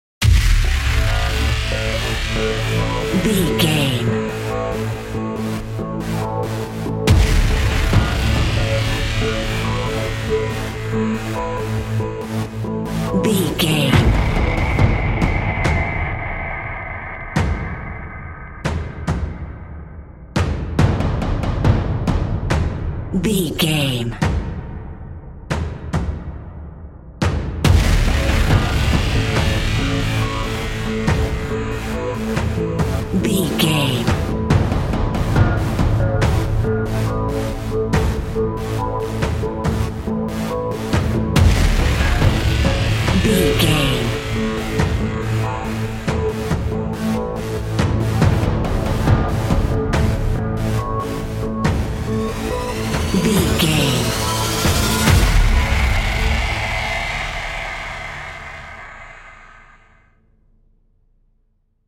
Epic / Action
Aeolian/Minor
Fast
synthesiser
percussion